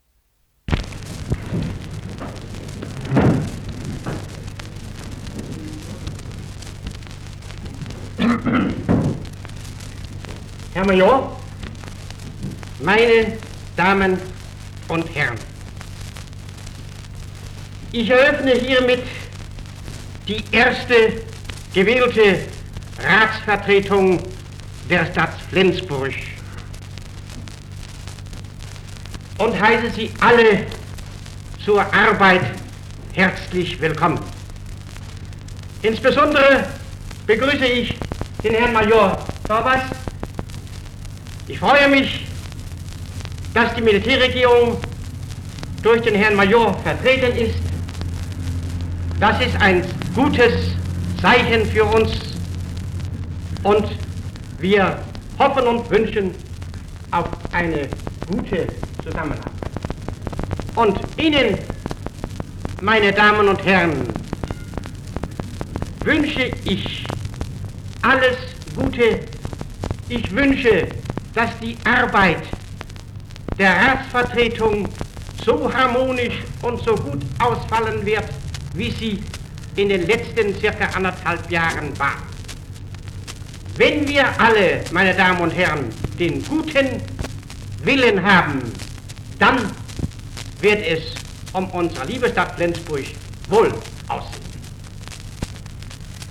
Åbning af første byrådsmøde i Flensborg efter 2. verdenskrig ved I.C. Møller 22. oktober 1946.